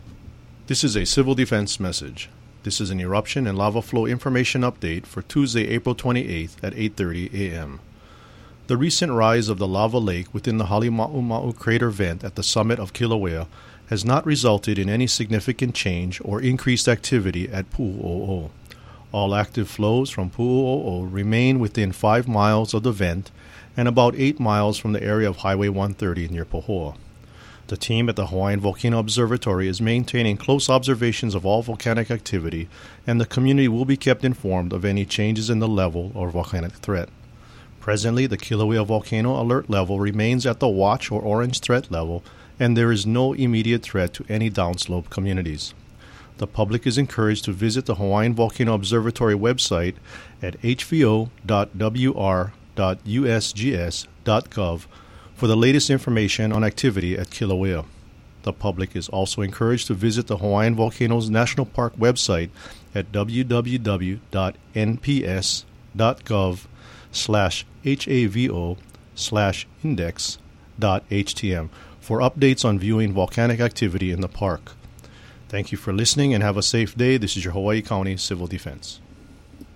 Hawaii County Civil Defense eruption and lava flow information update for 8:30 a.m., Tuesday April 28, 2015.